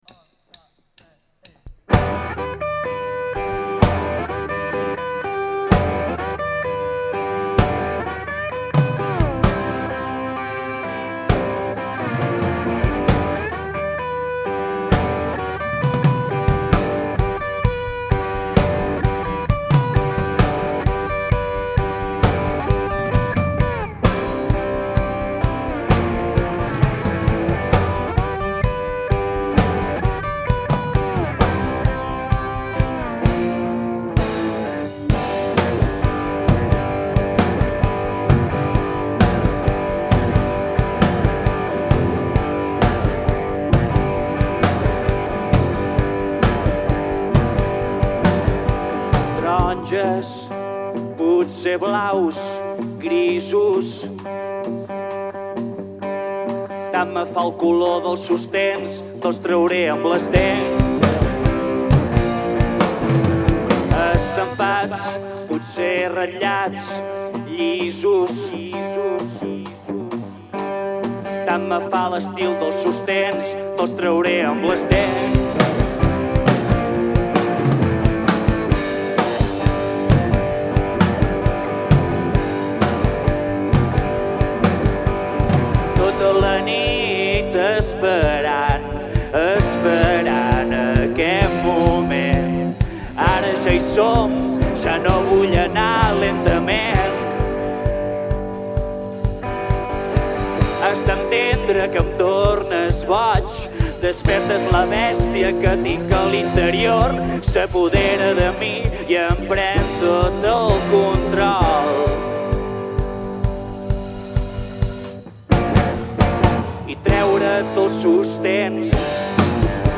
Amb només guitarra i bateria